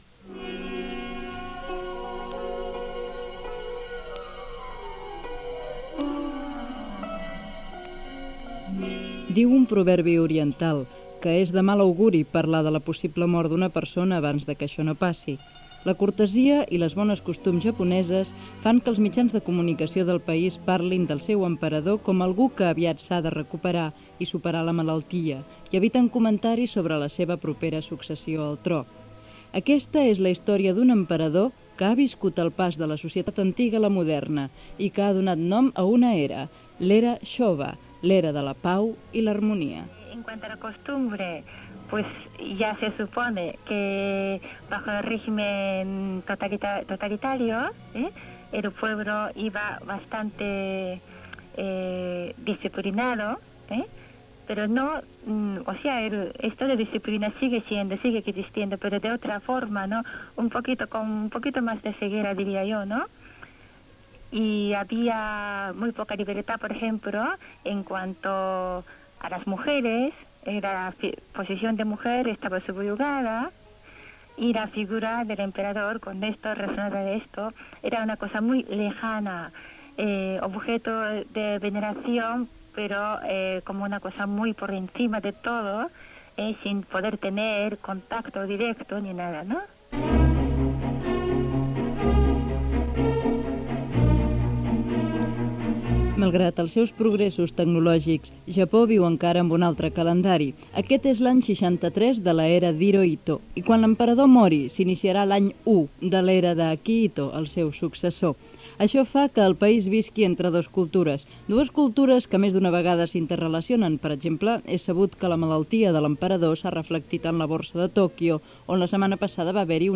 Reportatge sobre l'emperador japonès Hirohito, el seu estat de salut i la seva successió
Informatiu